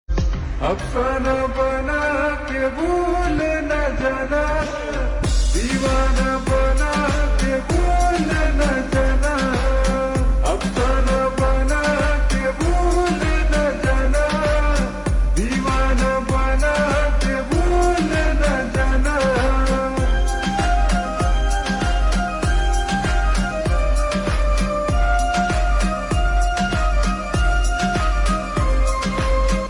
Hindi Songs
(Slowed + Reverb)